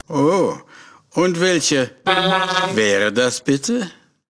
Kategorie:Fallout: Audiodialoge Du kannst diese Datei nicht überschreiben.